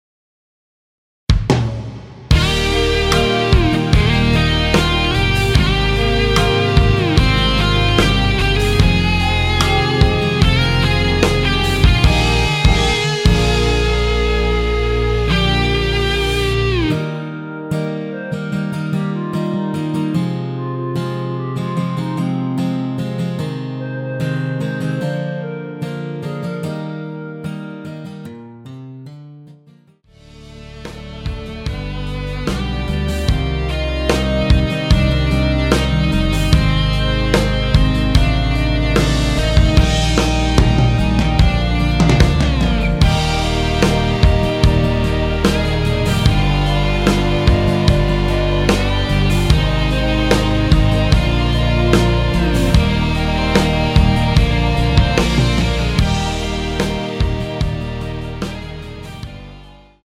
원키에서(-4)내린 멜로디 포함된 MR입니다.
Eb
앞부분30초, 뒷부분30초씩 편집해서 올려 드리고 있습니다.
중간에 음이 끈어지고 다시 나오는 이유는